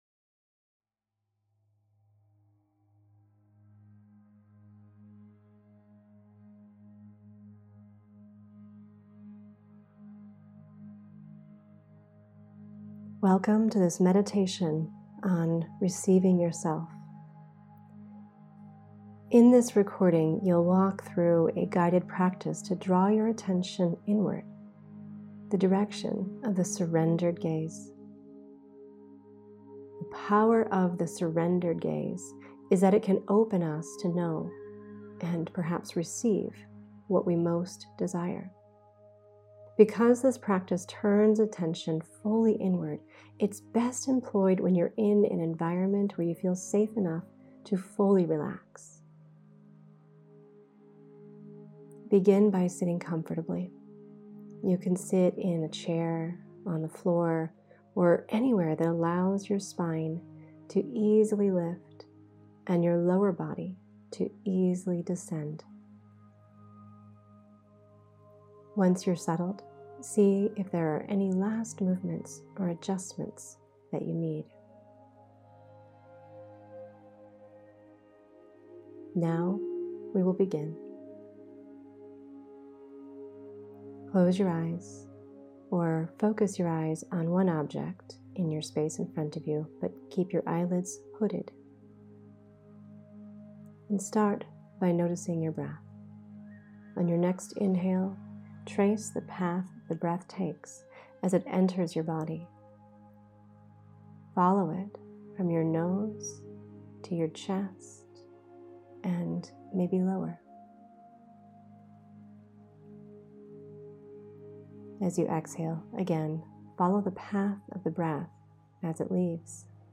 The direction of the receiving gaze is inward, and this guided meditation is a practice in learning how to move there.